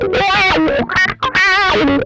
MANIC WAH 6.wav